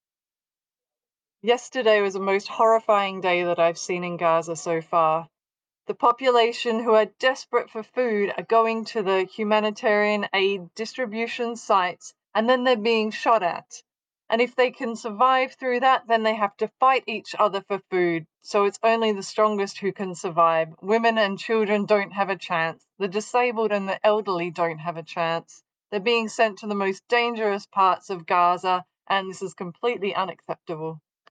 Voicenotes
recorded Monday 2nd June at Nasser Hospital, Gaza